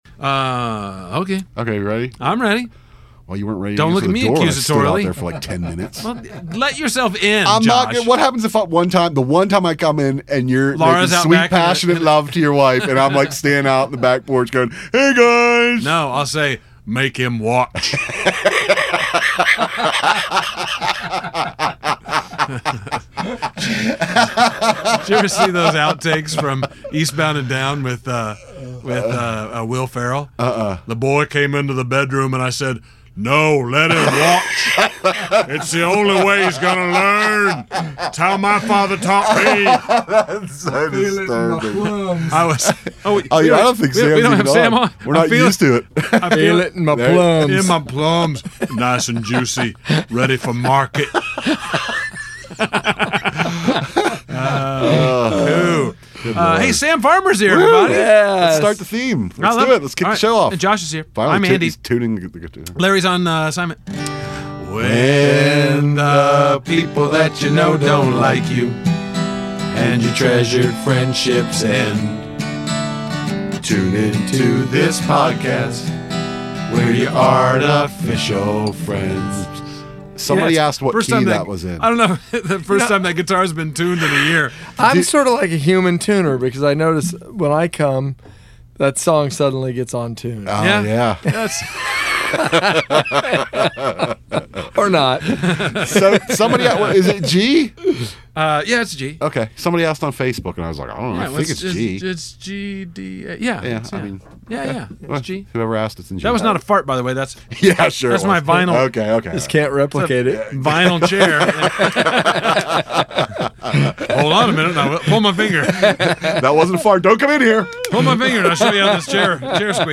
But we do talk about golf, Easter, and take your calls on the YAF line.